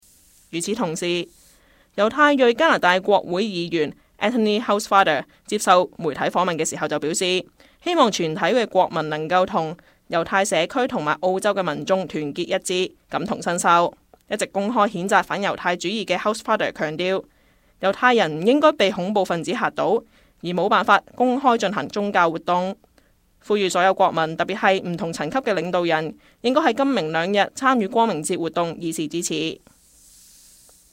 news_clip_24591.mp3